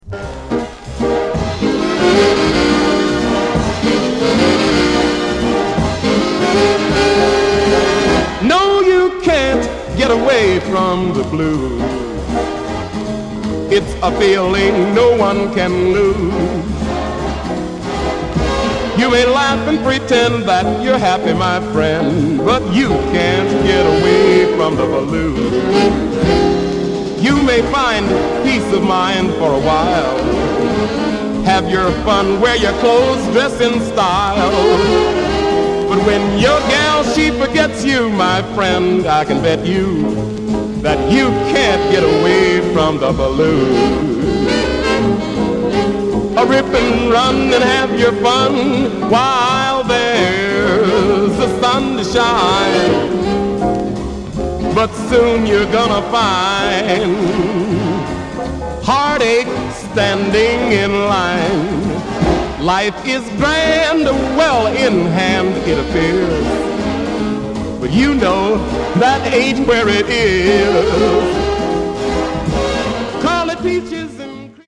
Up there as one of the gr8est jazz vocalists of all time!!